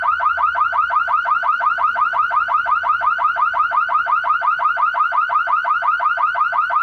Alarma-generica-2.mp3